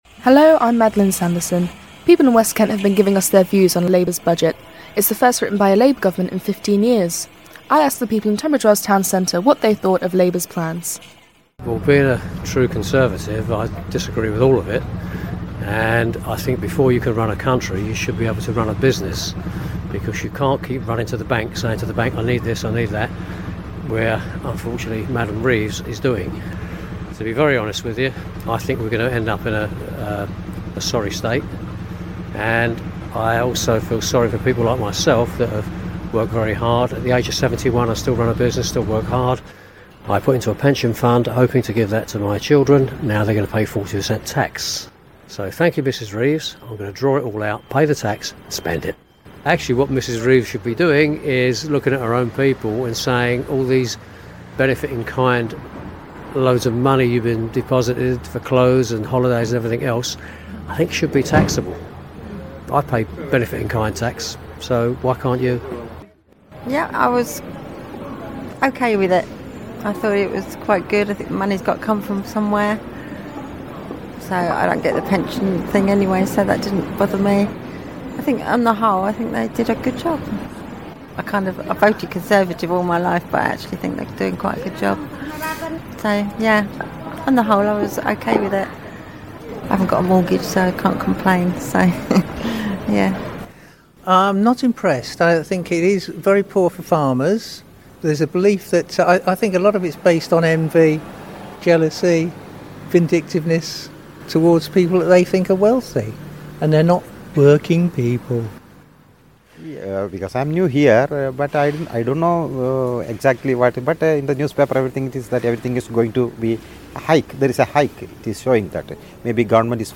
People in Tunbridge Wells have been giving their reaction to Rachel Reeves announcing Labour's first budget measures for 14 years.